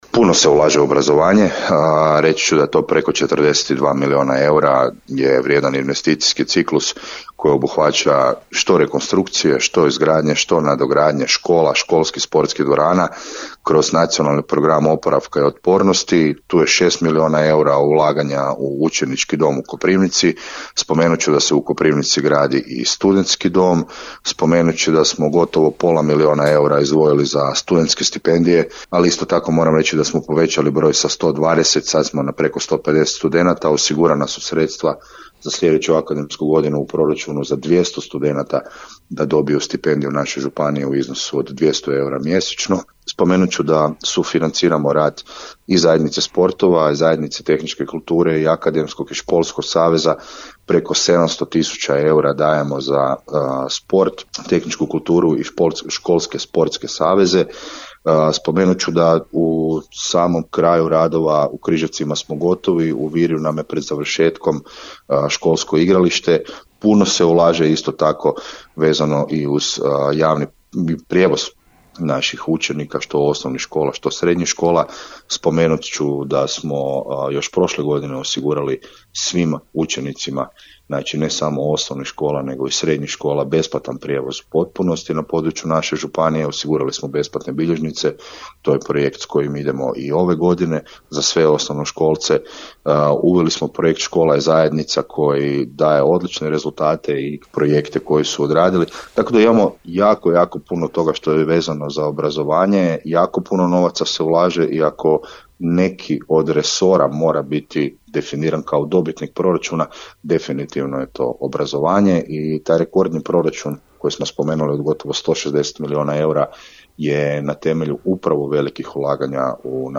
Koprivničko – križevačka županija u 2026.godinu ušla je s rekordnim proračunom, koji iznosi nešto više od 158 milijuna eura. Gostujući u emisiji „Aktualnosti iz županije”, župan Tomislav Golubić naglasio je kako je proračun prvenstveno razvojno usmjeren, s fokusom na konkretne programe i projekte koji će imati vidljiv utjecaj na kvalitetu života građana.
Župan je pritom pojasnio i kakva su ulaganja u obrazovanje na području županije: